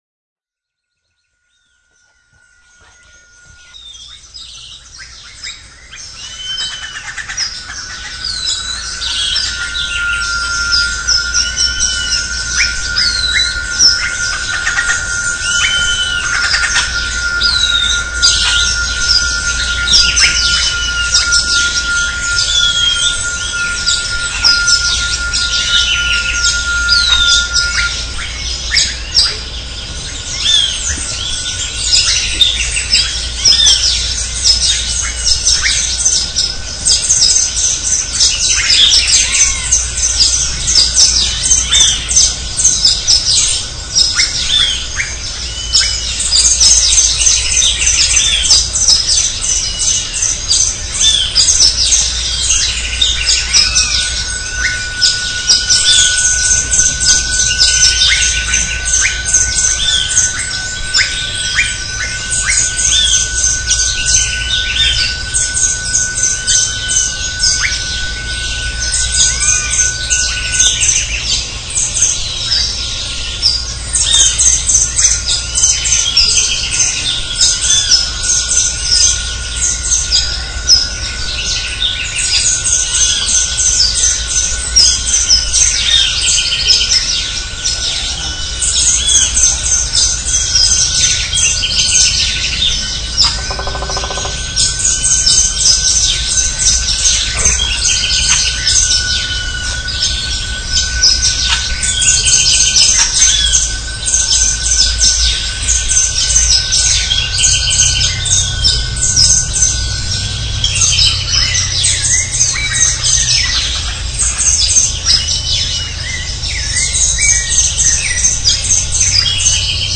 Geräuschdokumente der Natur.
Vögel, Frösche und eine Vielzahl im Verborgenen lebende Insekten sind die Musikanten.
Hier sehen Sie unsere kleinen Teichbiotope auf dem Gelände von CASA MARIA, wo die Aufnahmen der Frosch- und Vogelkonzerte gemacht wurden:
Frühstückskonzert ! Alle, die eine Stimme haben, machen mit ...